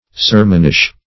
Sermonish \Ser"mon*ish\, a. Resembling a sermon.